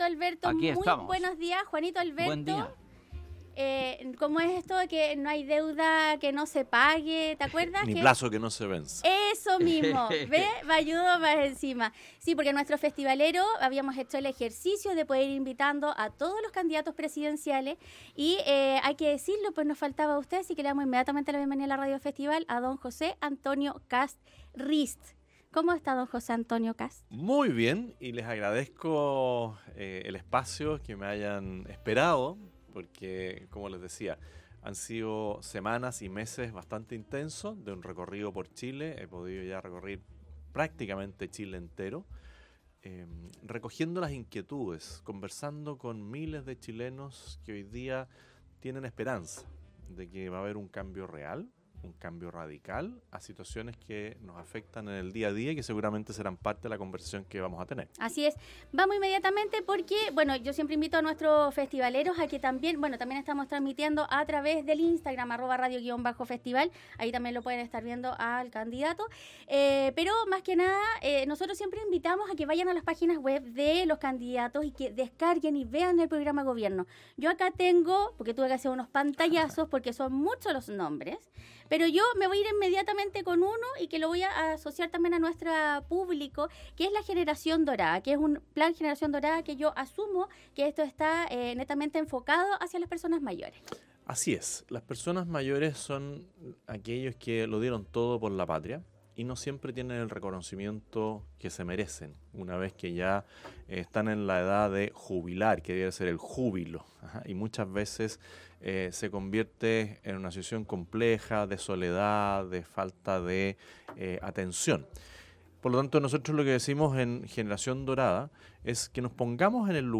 José Antonio Kast del partido Republicano estuvo en los estudios en Colores para abordar en profundidad su programa de Gobierno, las ayudas sociales y los damnificados